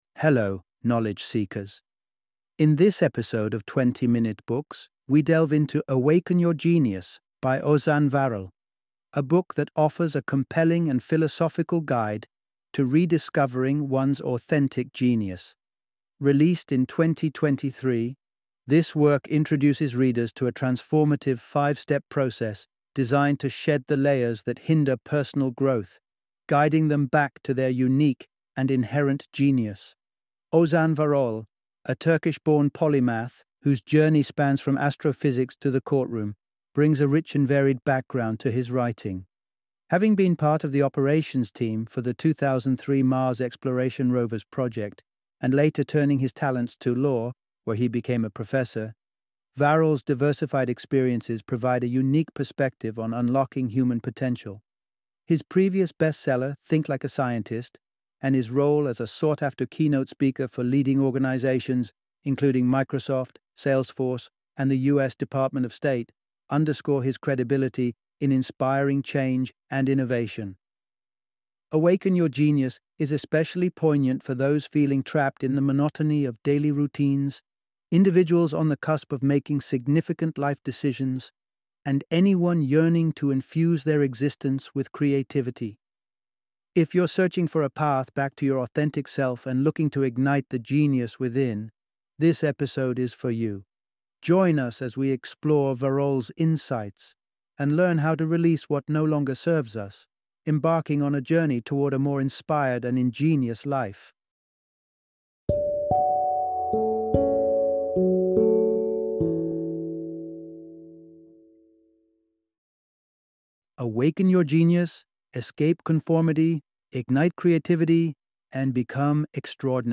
Awaken Your Genius - Audiobook Summary